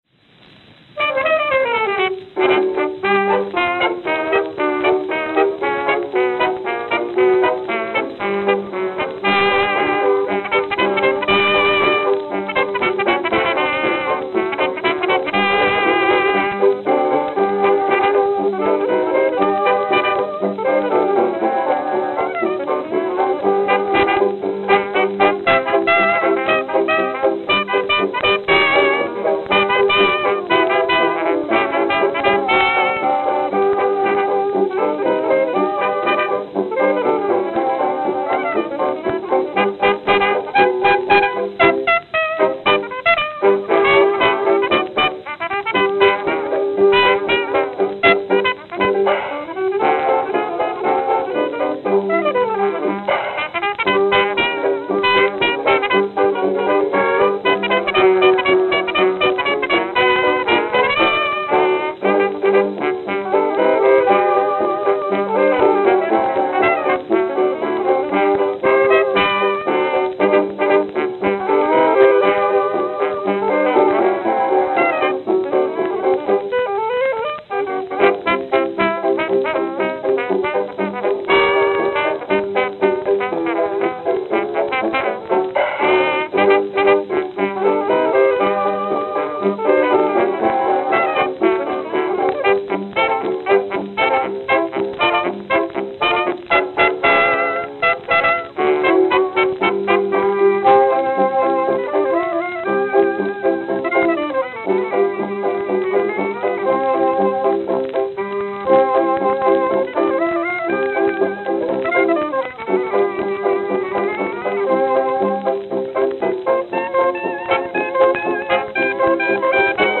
Acoustic Recordings
Note: Played at 78 RPM.